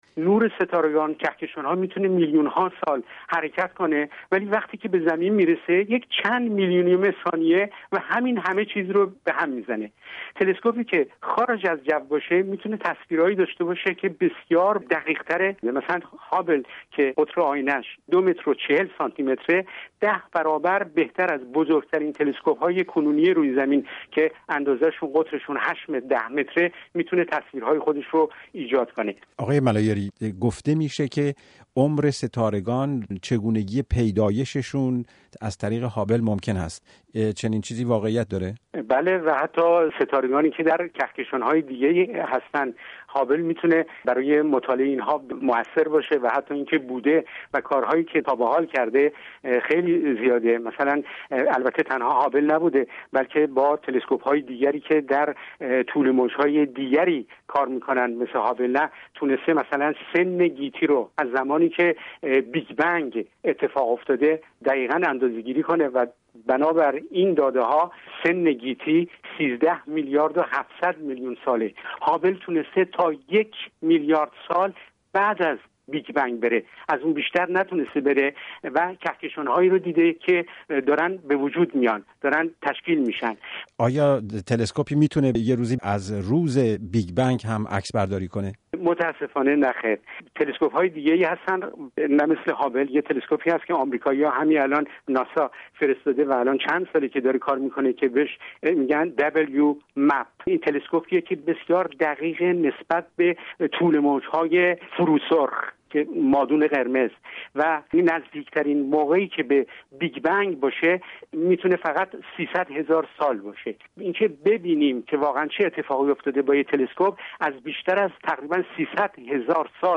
به تماشای ستارگان تا میلیون‌ها سال پیش- گفت‌وگو